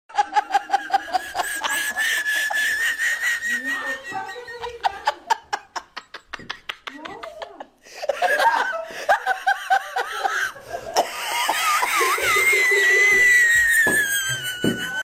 Sound Design For Video Games Sound Effects Free Download
Sound Design: Creative Cinematic FX